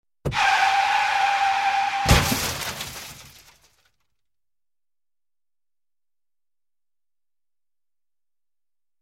Резкое торможение и громкий звук аварии